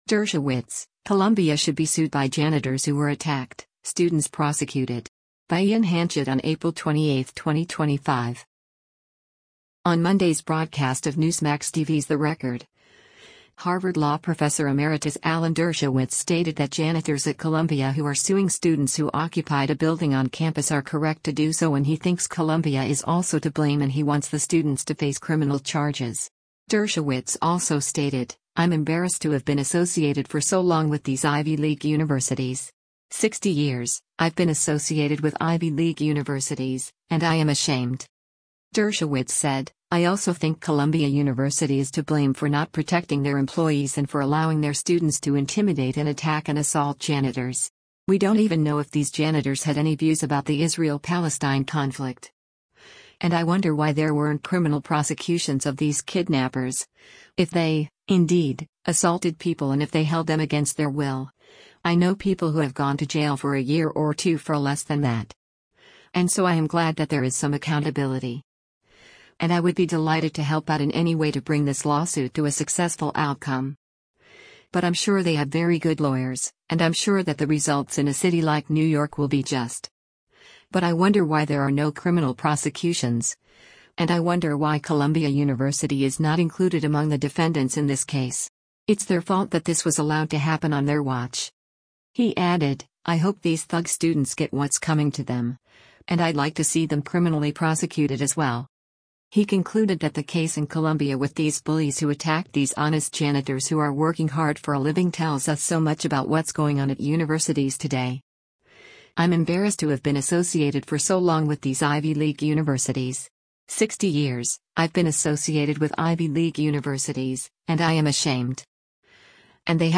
On Monday’s broadcast of Newsmax TV’s “The Record,” Harvard Law Professor Emeritus Alan Dershowitz stated that janitors at Columbia who are suing students who occupied a building on campus are correct to do so and he thinks Columbia is also to blame and he wants the students to face criminal charges.